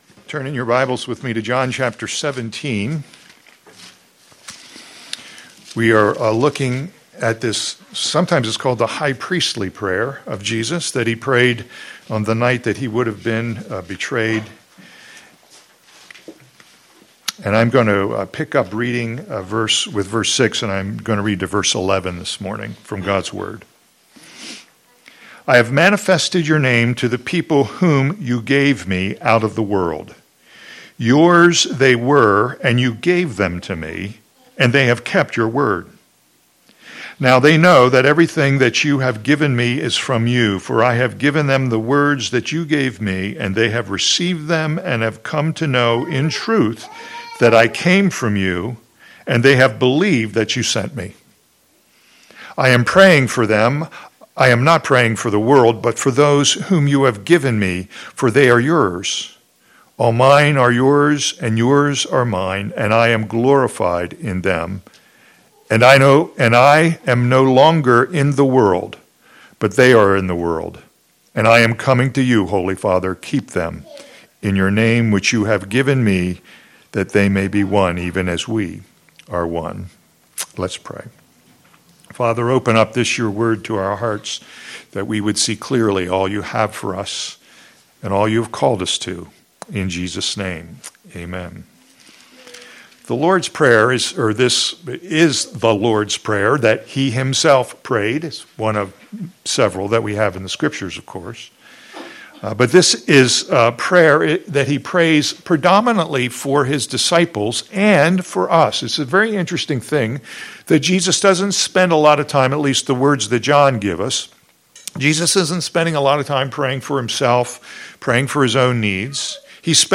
All Sermons The Lord’s Prayer for His Disciples